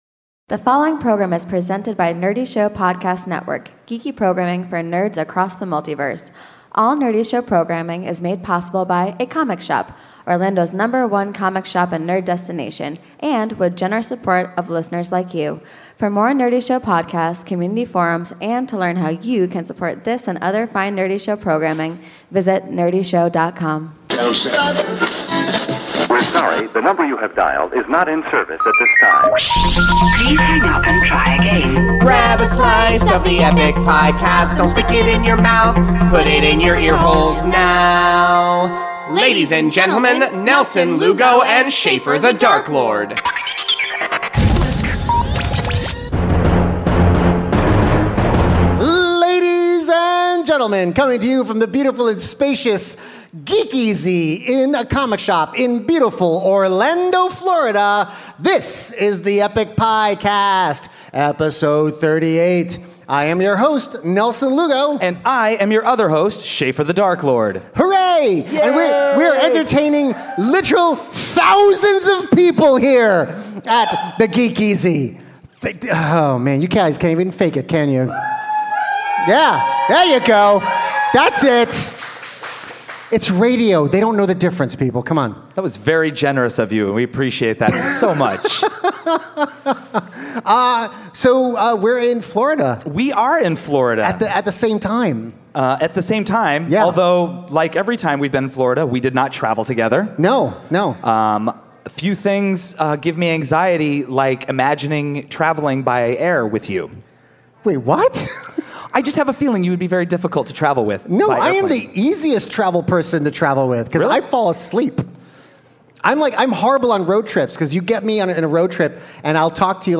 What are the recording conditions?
Live from Orlando